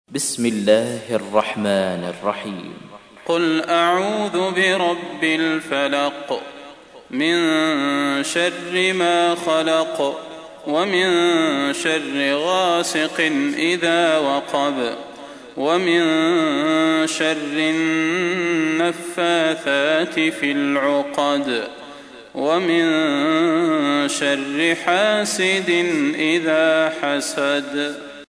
تحميل : 113. سورة الفلق / القارئ صلاح البدير / القرآن الكريم / موقع يا حسين